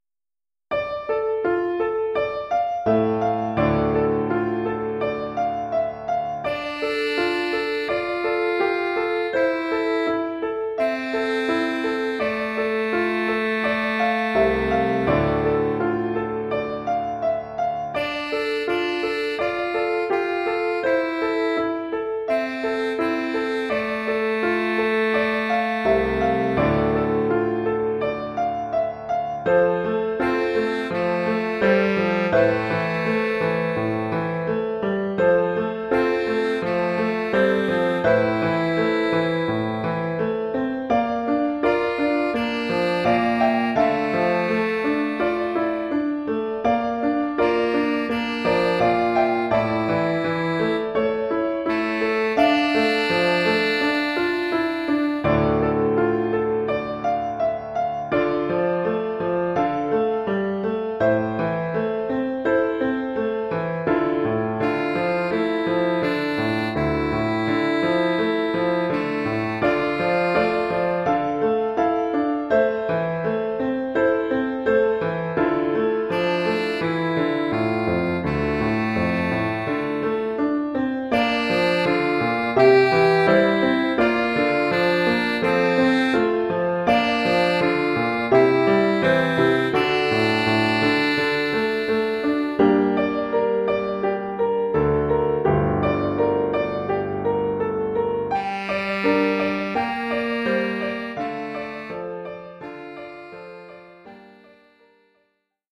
Formule instrumentale : Saxophone alto et piano
Oeuvre pour saxophone alto et piano.